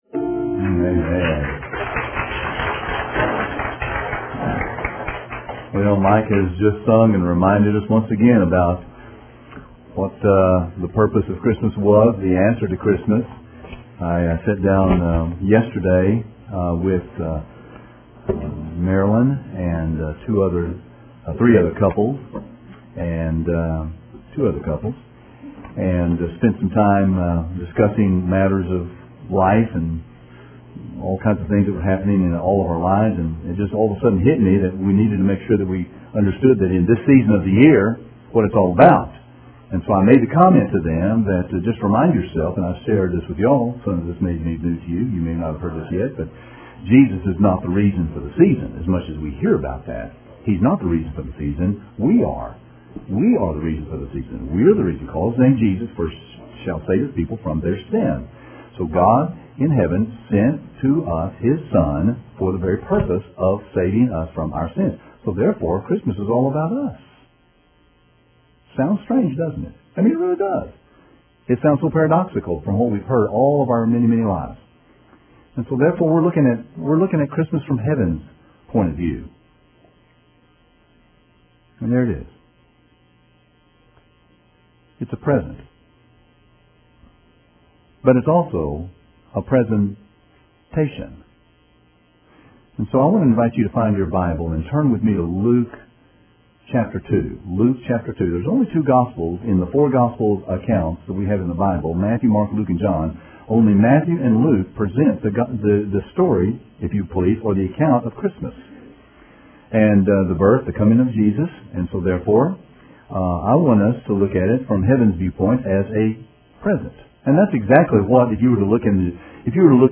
” Sermon Text …